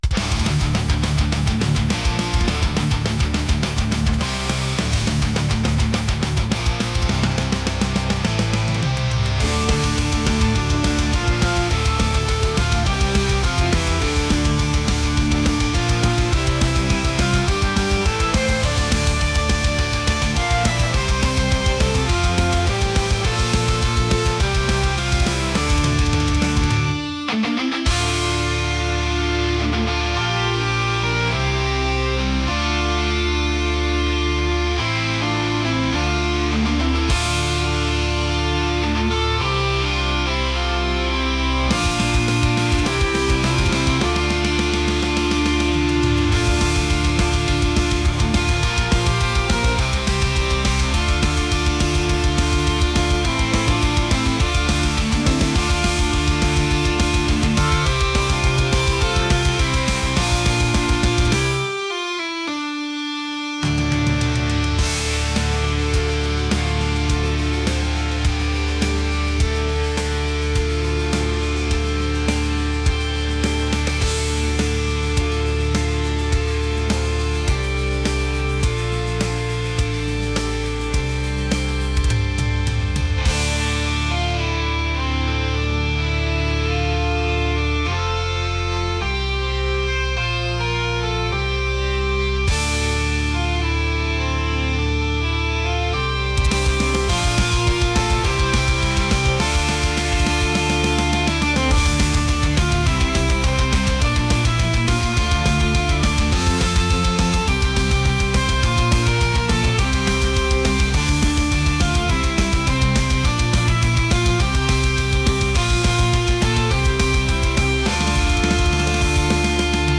heavy | metal | aggressive